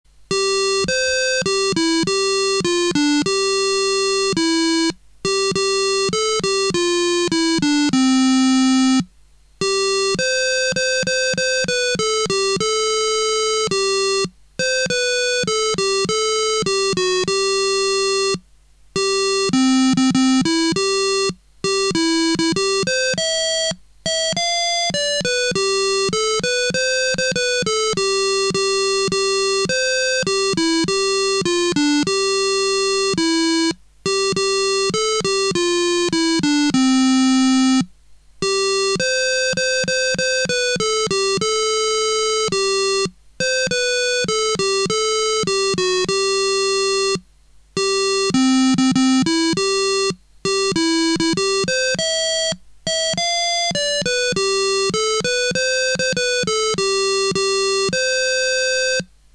1. Man kann zwischen den zwei Noten-Melodien wählen   oder    einer Melodie und einem Sirenenton.